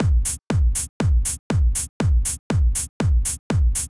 Drumloop 120bpm 01-C.wav